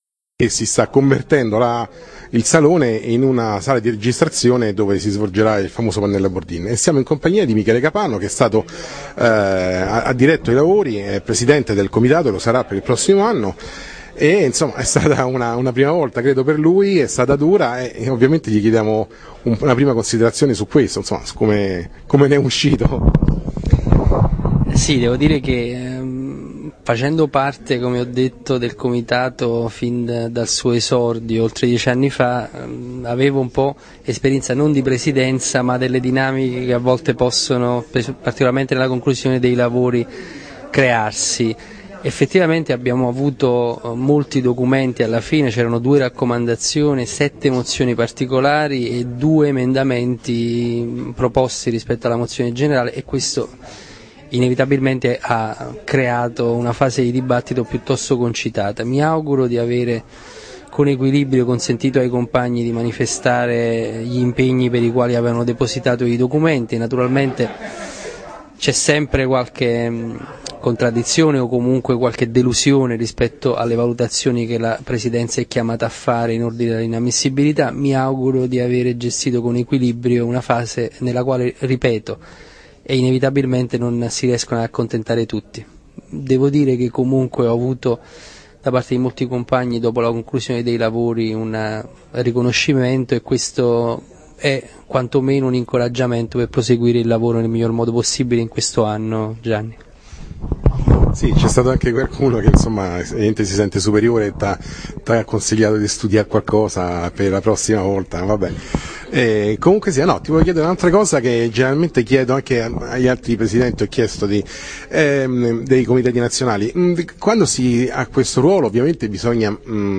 Comitato Nazionale Radicali Italiani del 1-2-3 febbraio 2013, Roma presso la sede del Partito Radicale, terza giornata.
Intervista